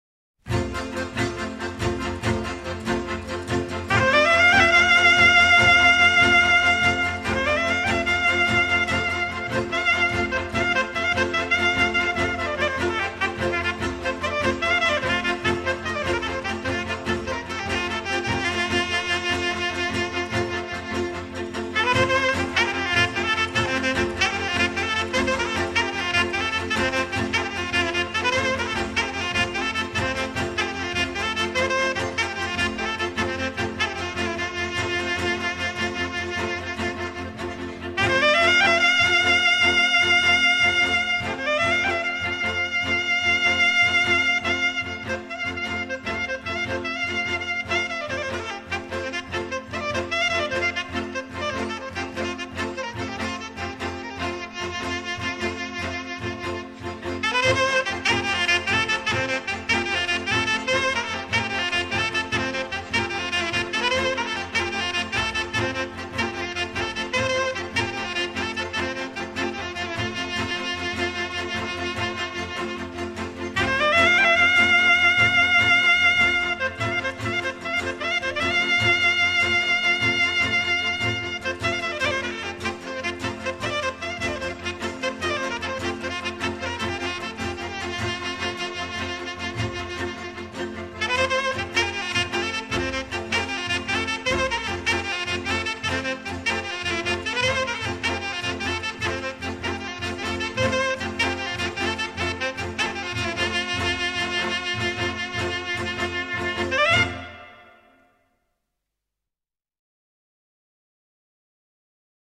cu acompaniamentul orchestrei